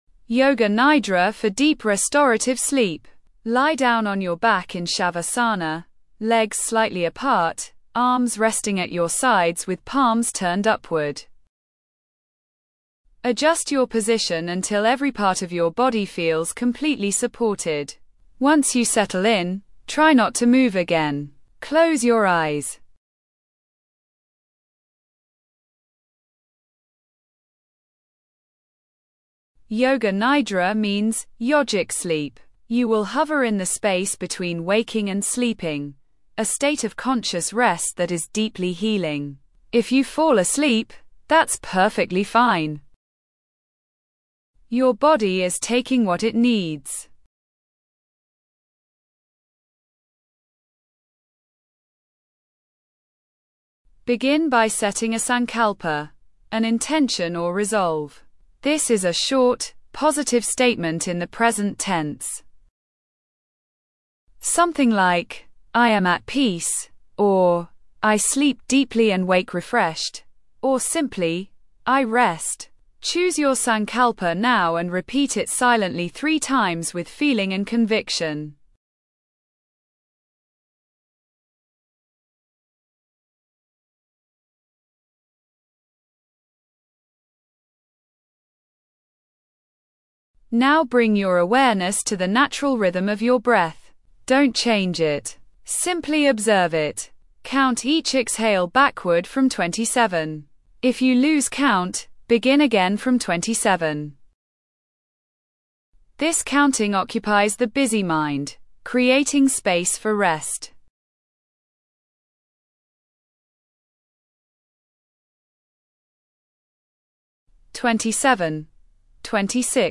A modified yoga nidra (yogic sleep) practice that guides you through progressive relaxation and intention-setting for profound, healing rest.